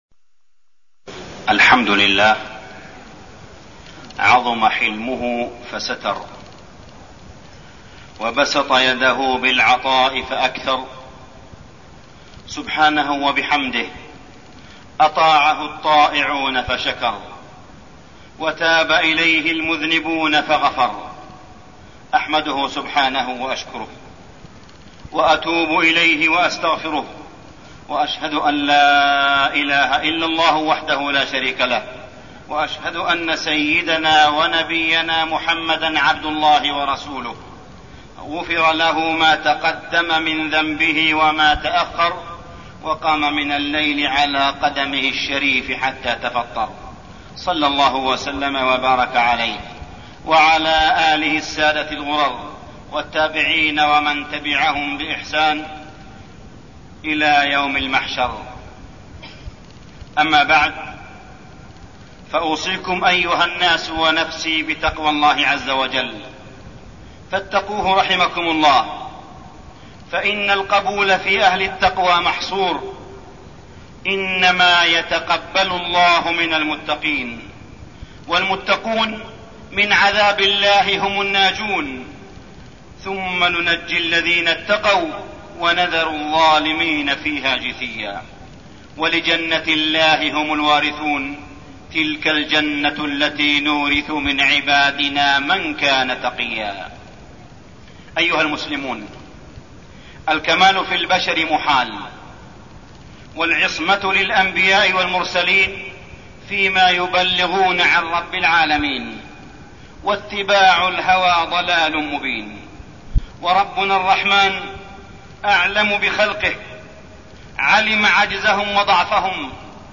تاريخ النشر ٢٣ رجب ١٤١٦ هـ المكان: المسجد الحرام الشيخ: معالي الشيخ أ.د. صالح بن عبدالله بن حميد معالي الشيخ أ.د. صالح بن عبدالله بن حميد مغفرة الله للذنوب